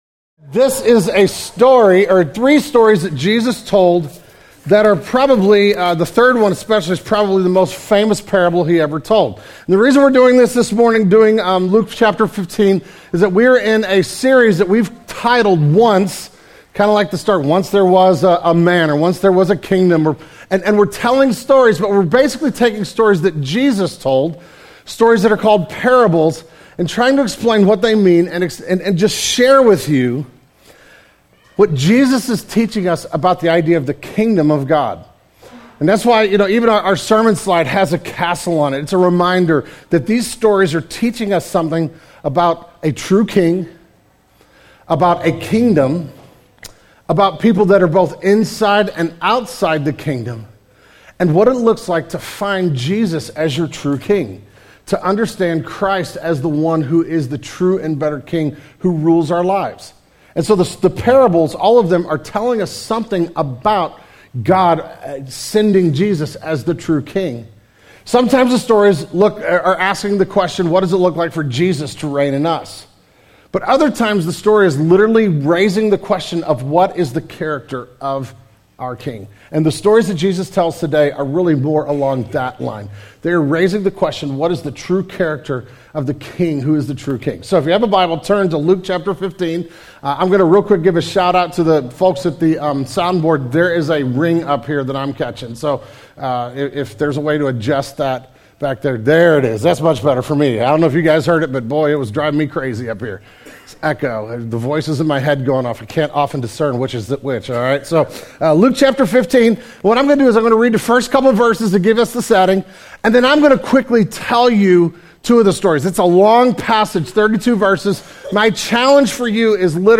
Suggested resource from today's sermon: "The Prodigal God" by Tim Keller Listen Download